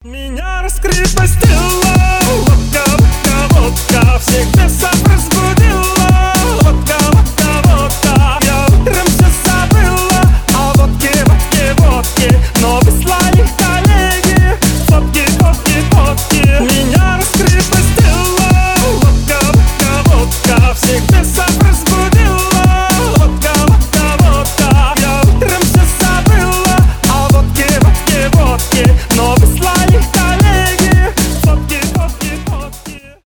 танцевальные
веселые